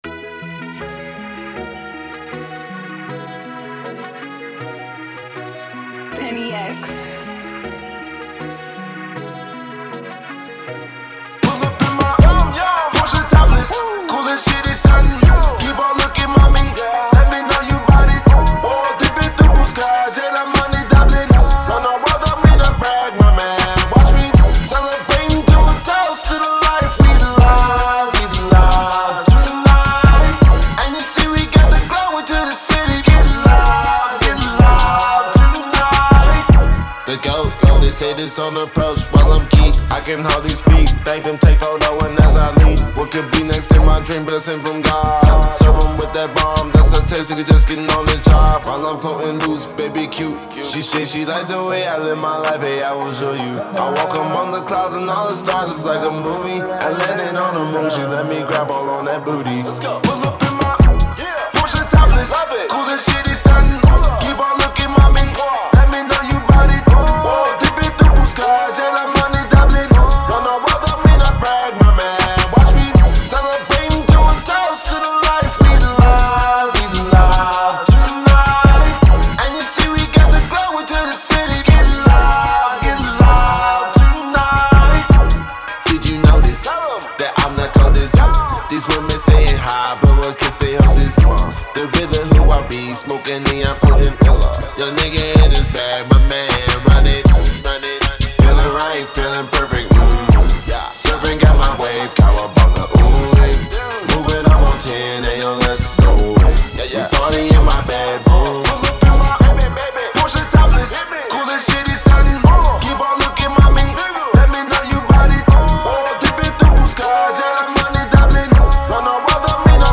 Genre : Hip-Hop, Rap